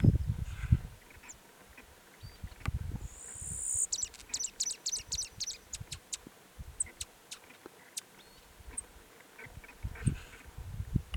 Ash-breasted Sierra Finch (Geospizopsis plebejus)
Sex: Male
Life Stage: Adult
Location or protected area: Reserva Natural Villavicencio
Condition: Wild